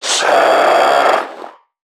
NPC_Creatures_Vocalisations_Infected [81].wav